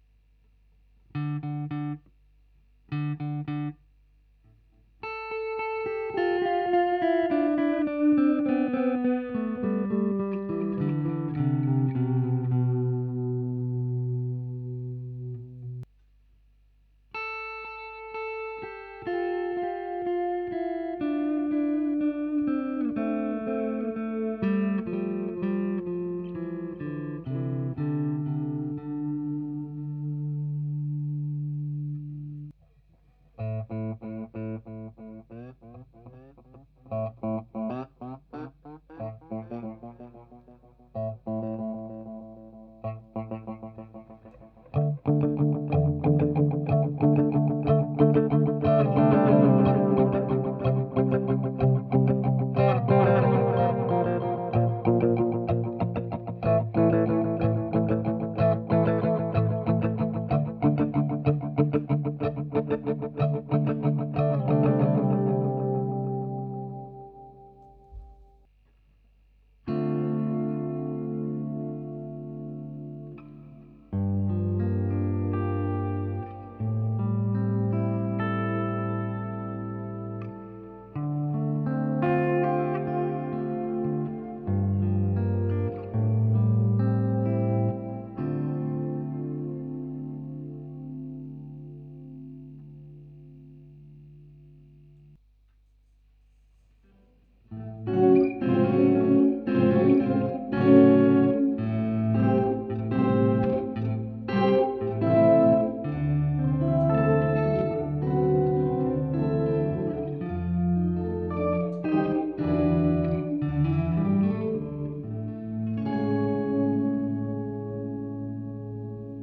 This one is is a multi effect which can store 24 patches, each of which you design in software.